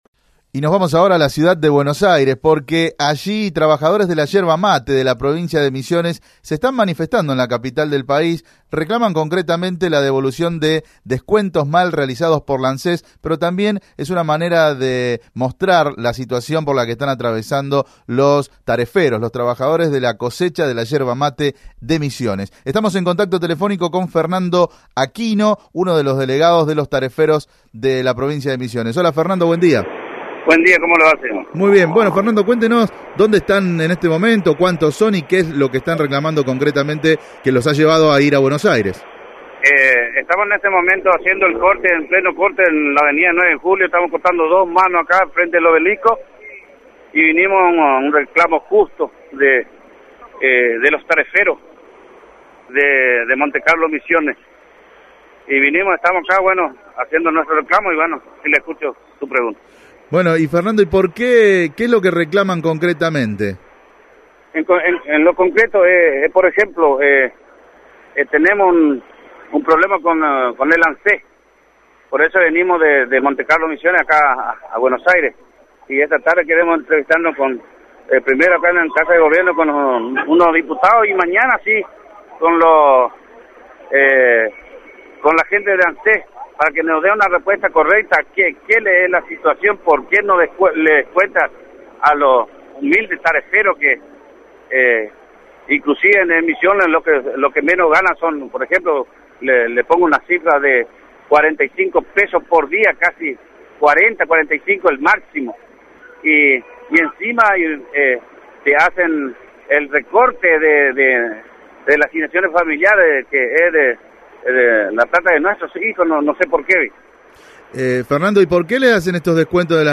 Tareferos, Entrevista